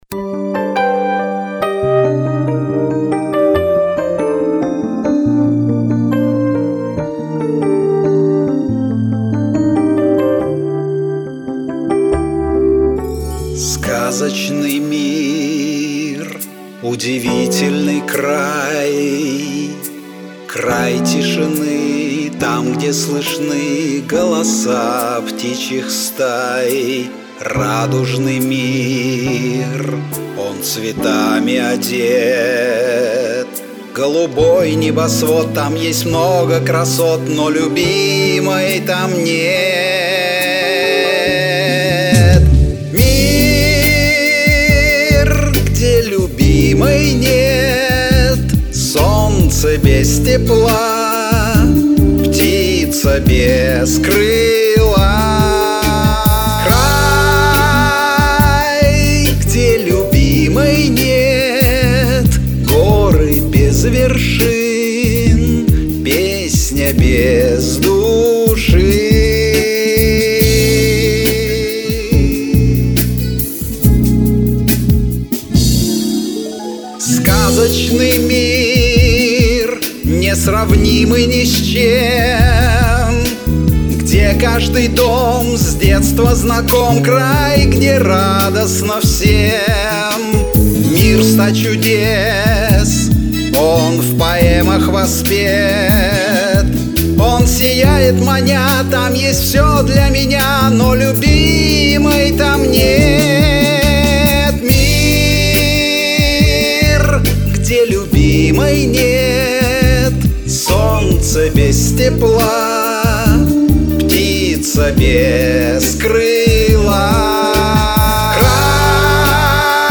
в мужском варианте КВН